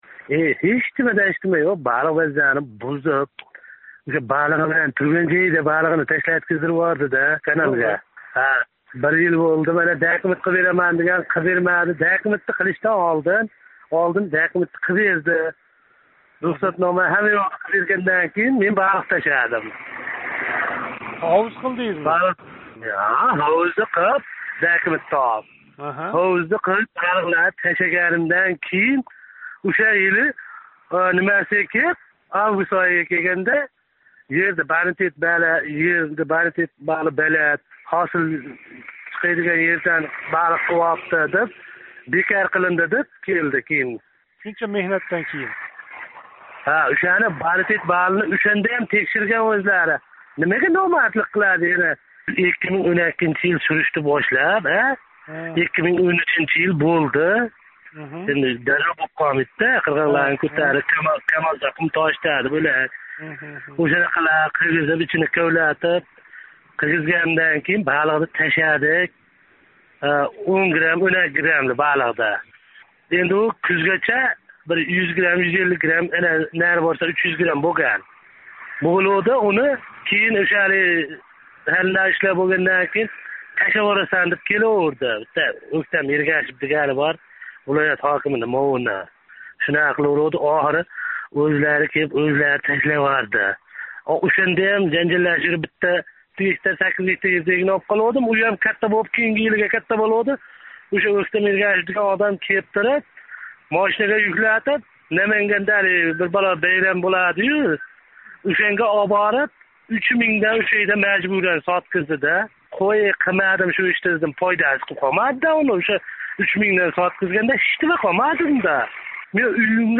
OzodMikrofon га қўнғироқ қилиб арз қилган тадбиркорлар маҳаллий ҳокимларнинг уларнинг фаолиятига тўсқинлик қилиб, катта қарзларга ботиргани ҳақида шикоят қилди.